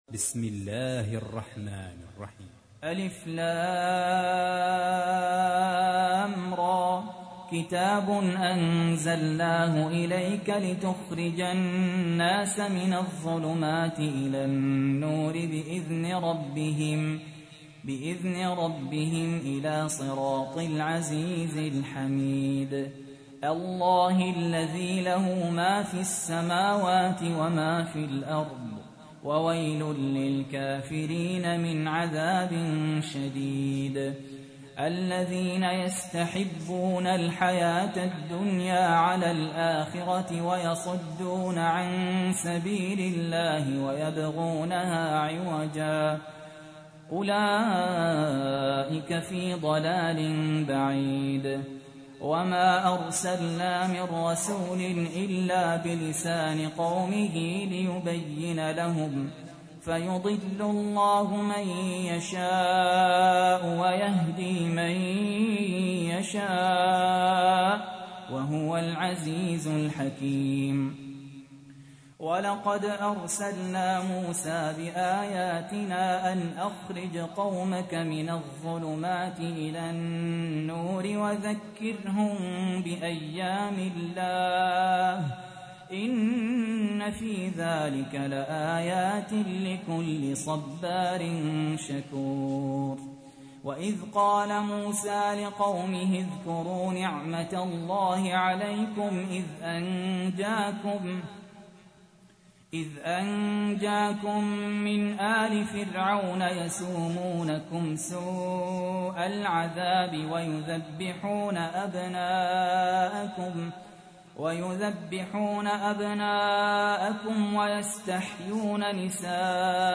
تحميل : 14. سورة إبراهيم / القارئ سهل ياسين / القرآن الكريم / موقع يا حسين